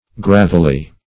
Gravelly \Grav"el*ly\, a.
gravelly.mp3